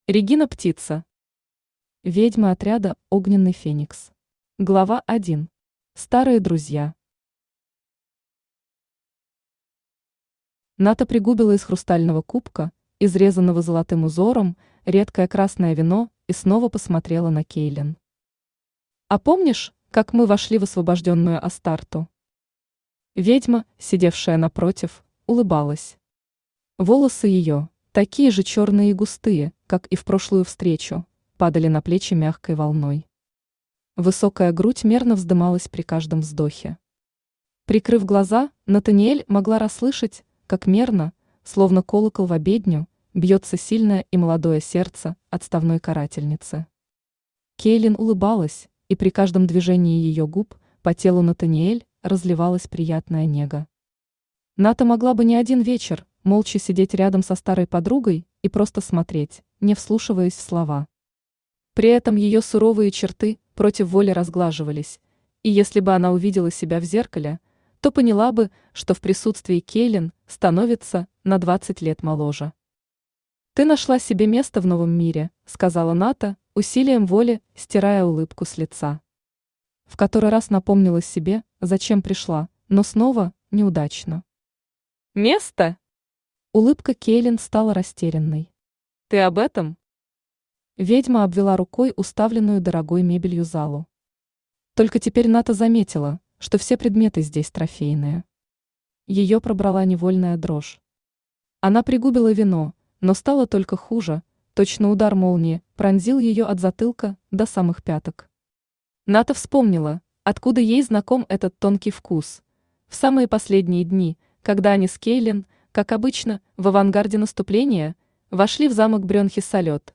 Аудиокнига Ведьмы Отряда Огненный Феникс | Библиотека аудиокниг
Читает аудиокнигу Авточтец ЛитРес